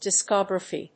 /ˌdɪsˈkɑɡrəfi(米国英語), ˌdɪsˈkɒɡrəfi(オーストラリア英語)/